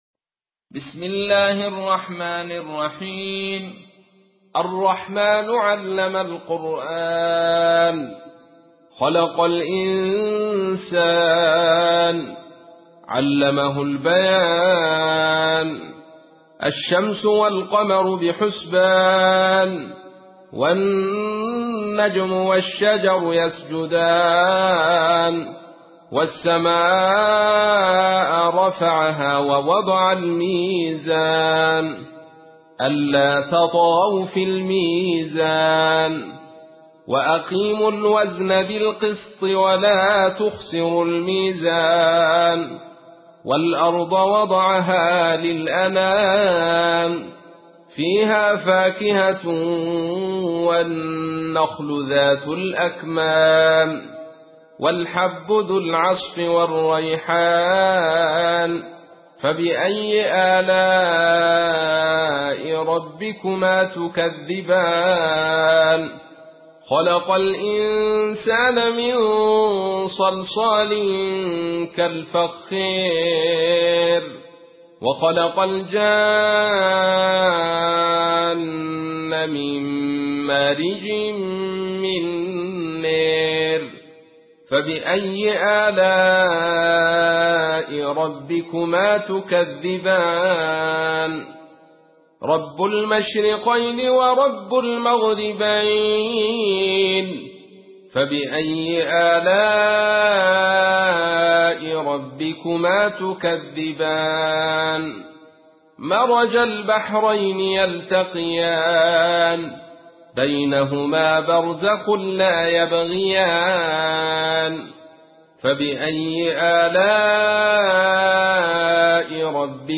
تحميل : 55. سورة الرحمن / القارئ عبد الرشيد صوفي / القرآن الكريم / موقع يا حسين